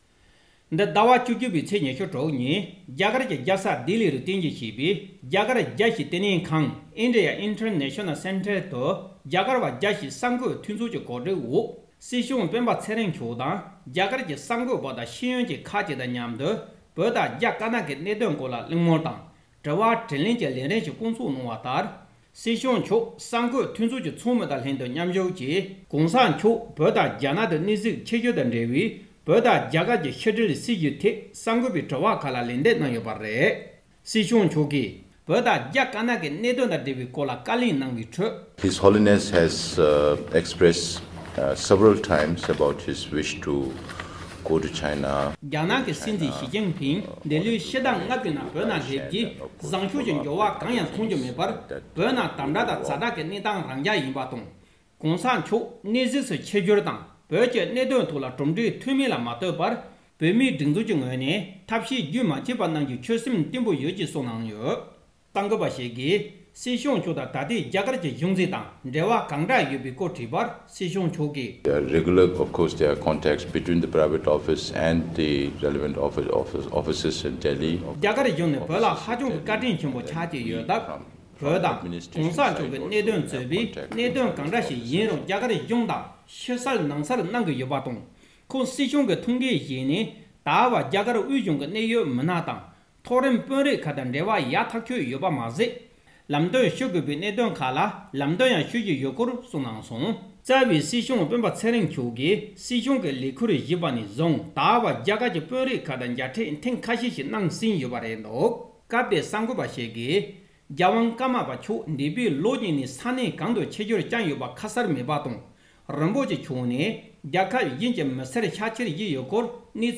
འདི་ཟླ་བ་༡༡ ཚེས་༢༦ ཉིན་རྒྱ་གར་གྱི་རྒྱལ་ས་ལྡི་ལི་རུ་བརྟེན་གཞིར་བྱས་པའི་རྒྱ་གར་རྒྱལ་སྤྱིའི་བསྟི་གནས་ཁང་(India International Centre) དུ་རྒྱ་གར་བ་རྒྱལ་སྤྱིའི་གསར་འགོད་མཐུན་ཚོགས་ཀྱིས་གོ་སྒྲིག་འོག་སྲིད་སྐྱོང་སྤེན་པ་ཚེ་རིང་མཆོག་དང་རྒྱ་གར་གྱི་གསར་འགོད་པ་དང་ཤེས་ཡོན་ཅན་ཁག་ཅིག་དང་མཉམ་དུ་བོད་དང་རྒྱ་དཀར་ནག་གི་གནད་དོན་སྐོར་ལ་གླེང་མོལ་དང་དྲི་བ་དྲིས་ལན་གྱི་ལས་རིམ་ཞིག་བསྐོང་ཚོགས་གནང་བ་ལྟར།